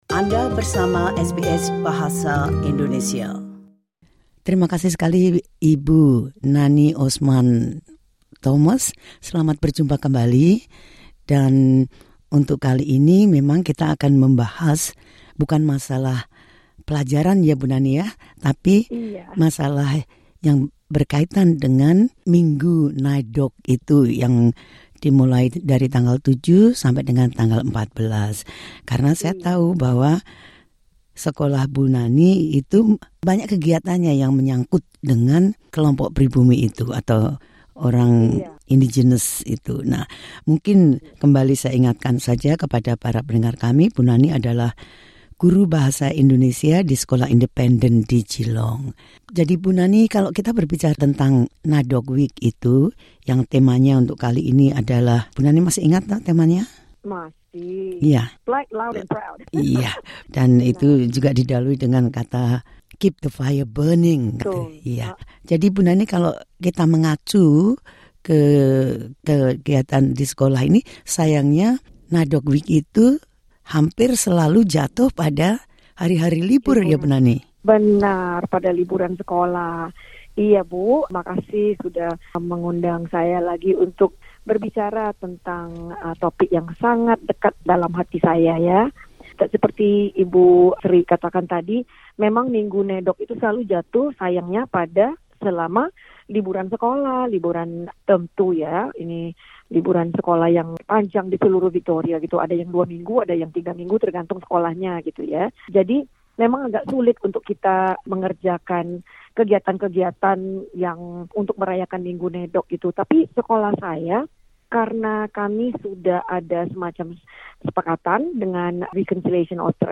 Dalam wawancara baru-baru ini dengan SBS Audio Indonesian Program